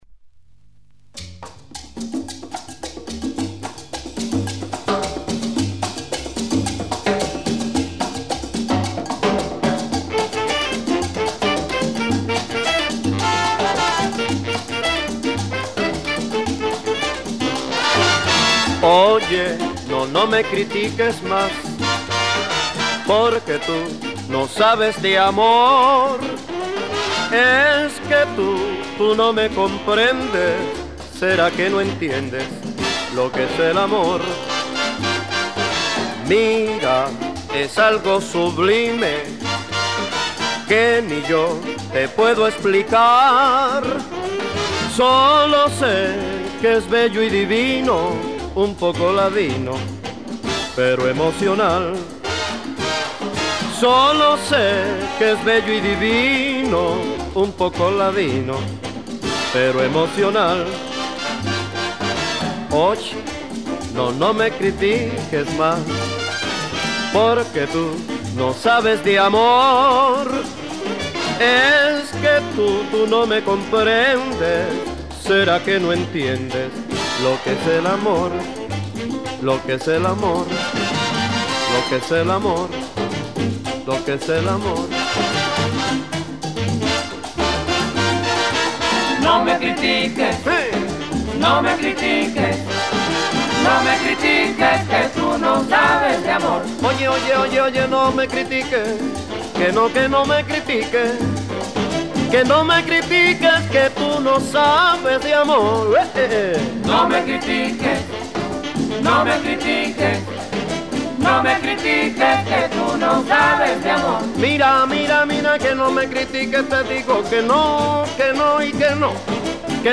CUBA / キューバ
当時のキューバのダンスムーブメントをけん引してきた男性ボーカリスト
1960年前後の「フィーリン」ムーブメントの際にも活躍